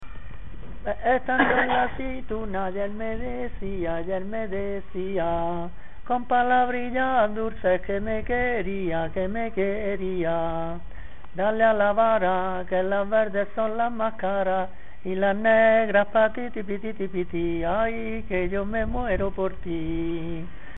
Materia / geográfico / evento: Canciones populares Icono con lupa
Agrón (Granada) Icono con lupa
Secciones - Biblioteca de Voces - Cultura oral